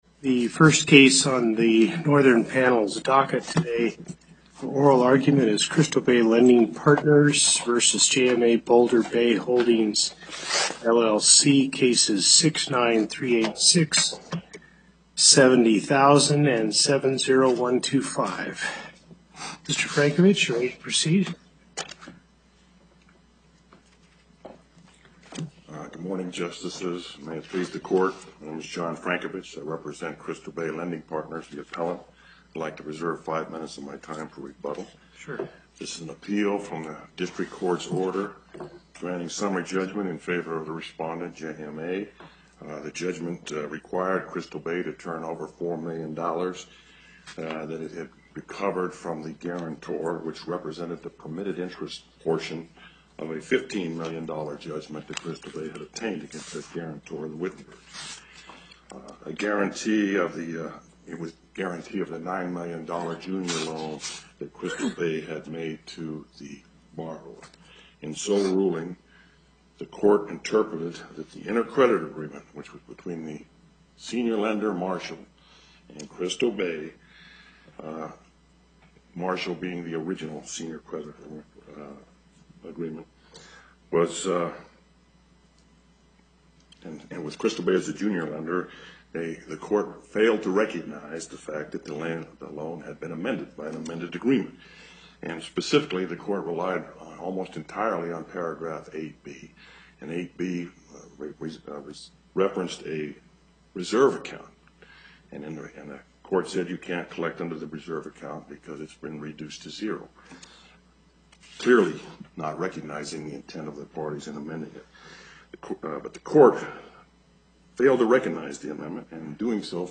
Location: Carson City Before the Northern Nevada Panel, Justice Hardesty presiding